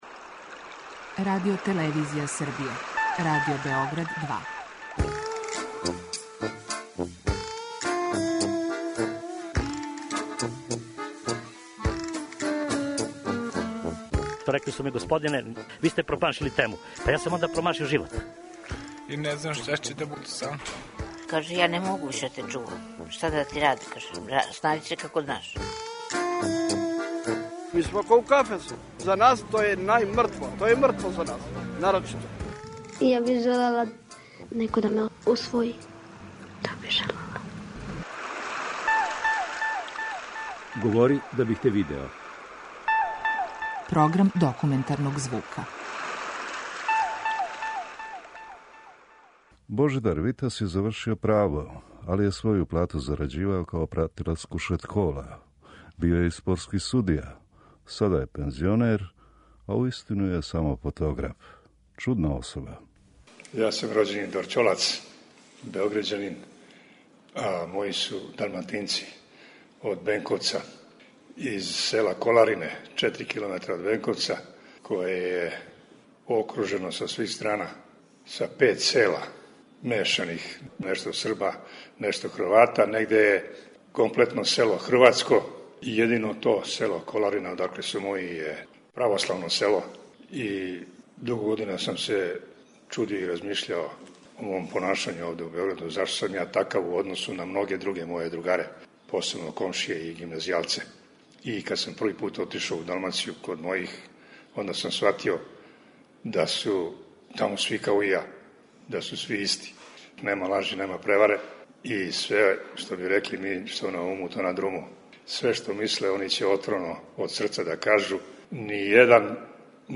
Документарни програм